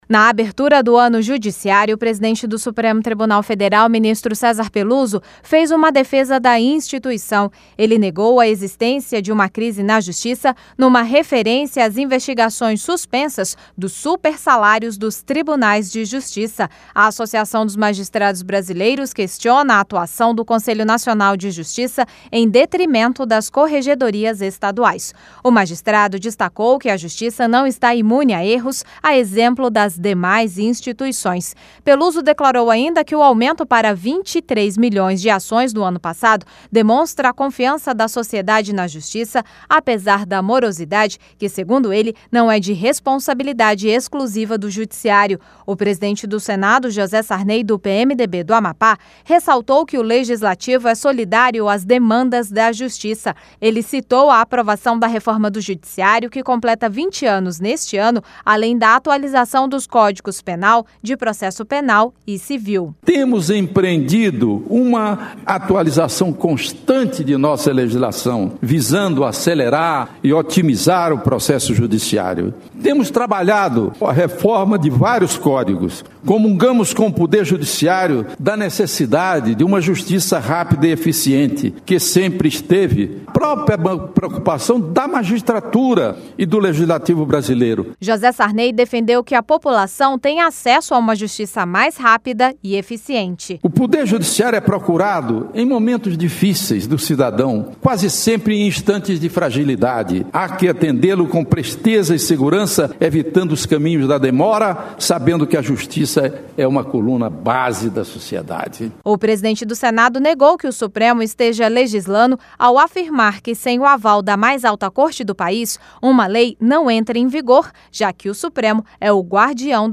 O PRESIDENTE DO SENADO, JOSÉ SARNEY, DO PMDB DO AMPÁ, DESTACOU O TRABALHO DO LEGISLATIVO PARA TORNAR A JUSTIÇA MAIS RÁPIDA, DURANTE A CERIMÔNIA DE ABERTURA DOS TRABALHOS JUDICIÁRIOS, NESTA QUARTA-FEIRA PELA MANHÃ.